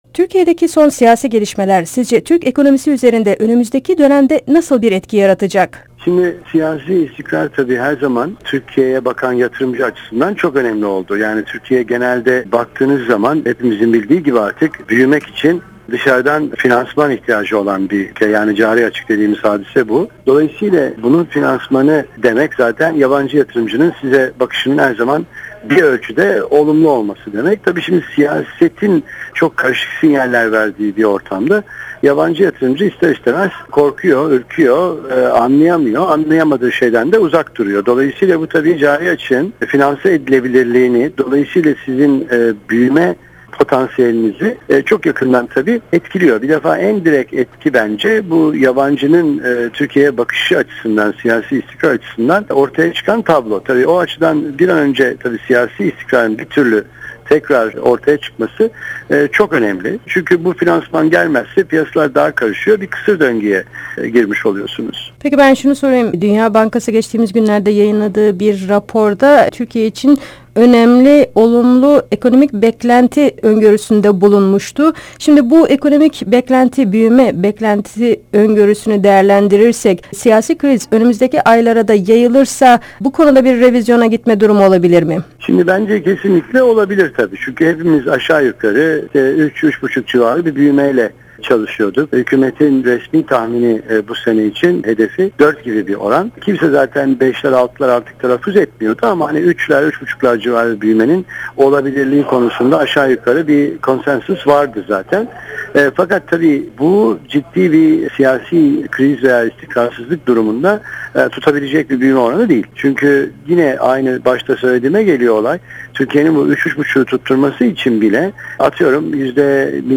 Söyleşiden alıntıları ise aşağıda okuyabilirsiniz.